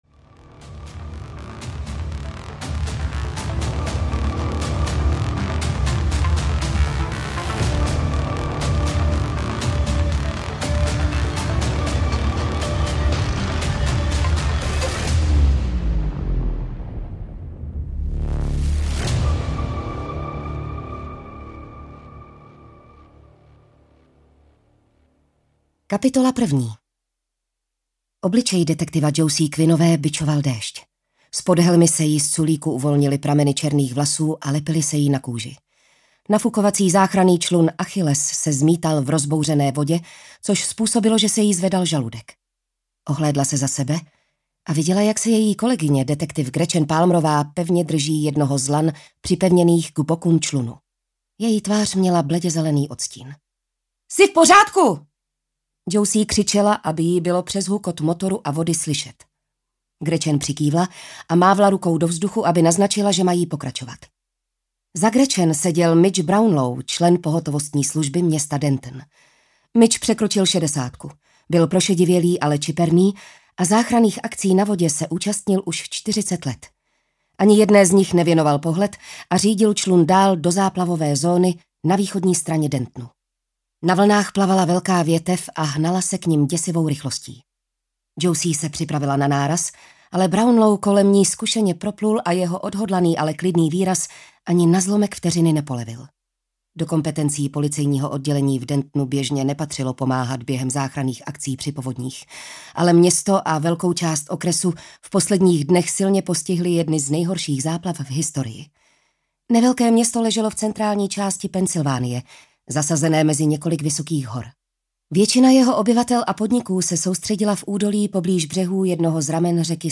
Krvavá spása audiokniha
Ukázka z knihy